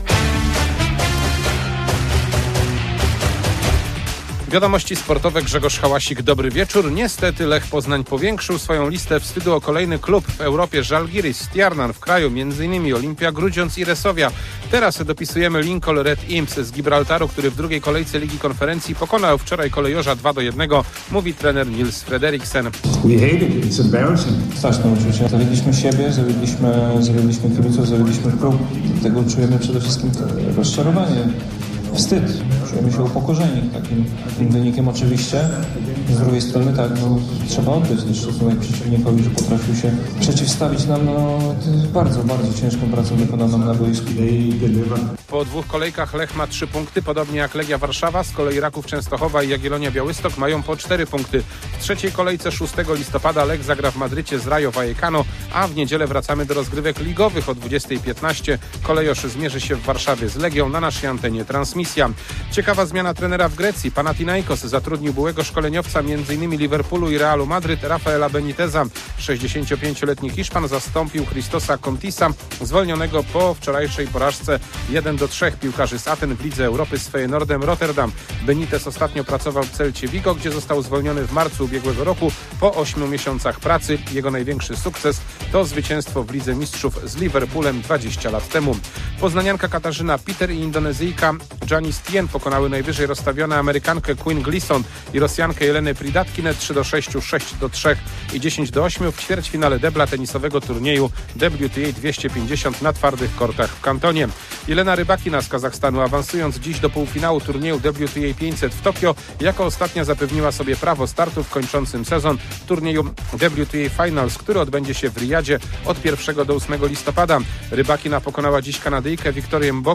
24.10.2025 SERWIS SPORTOWY GODZ. 19:05